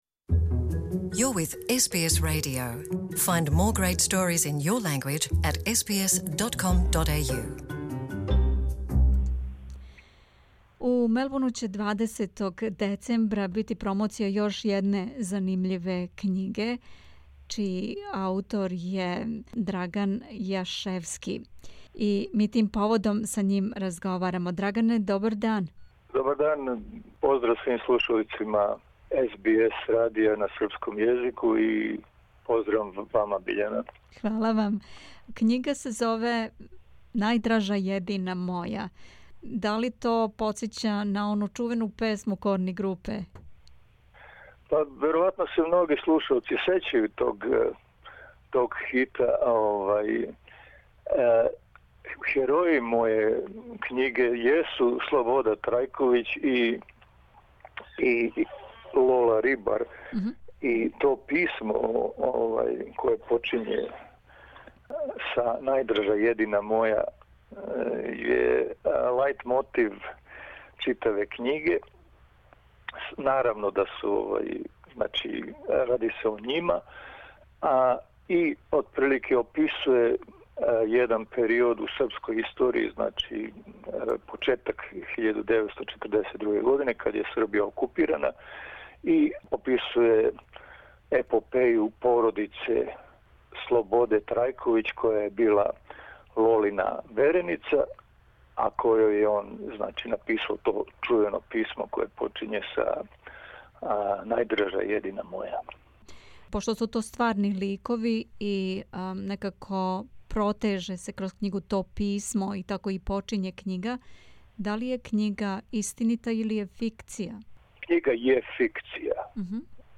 intervju_.mp3